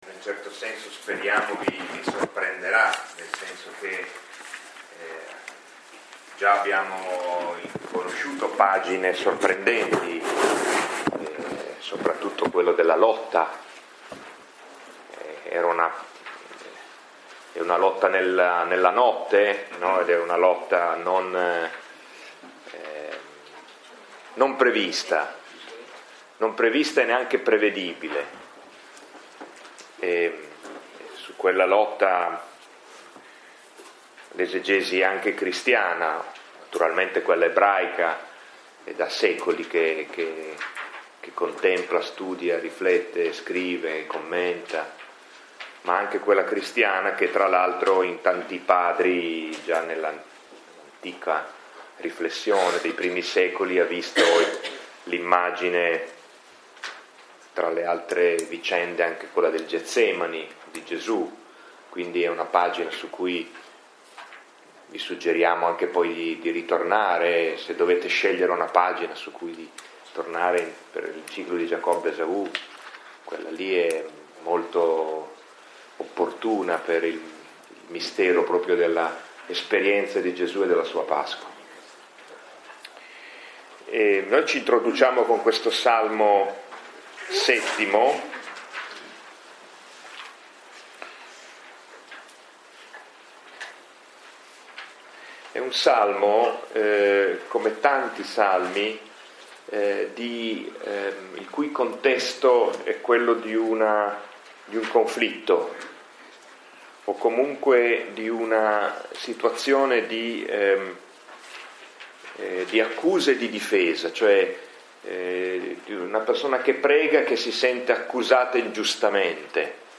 Lectio 6 – 15 marzo 2015 – Antonianum – Padova